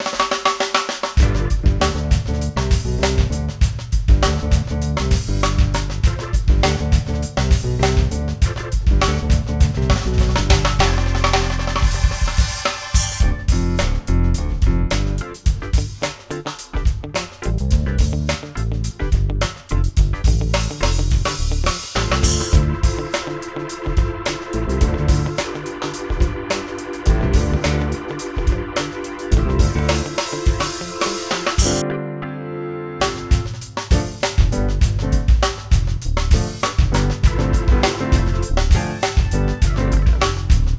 Pop rock 1 (bucle)
pop
melodía
repetitivo
rítmico
rock
sintetizador
Sonidos: Música